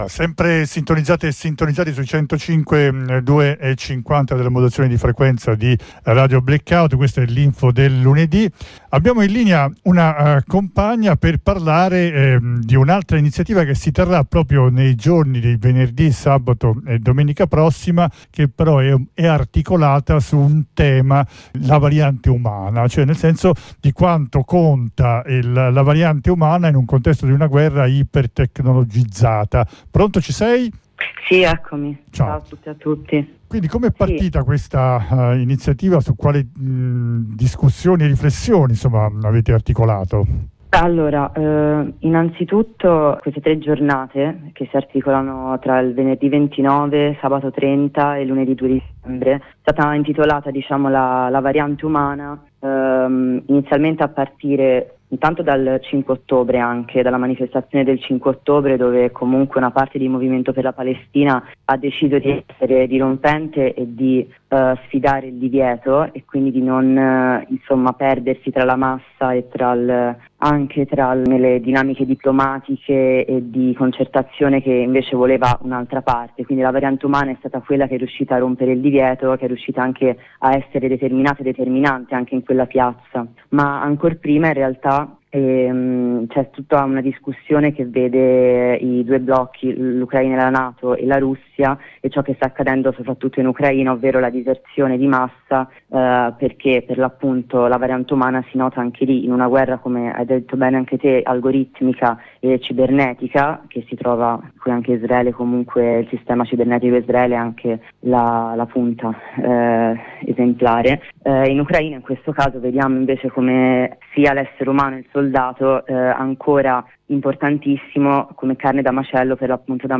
Ne parliamo con una compagna